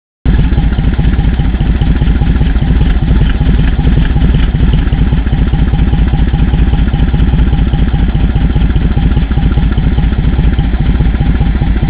アイドリング時の音量は２段階くらい小さくなった気がします。
バッフル交換後アイドリング音
muffler722.wav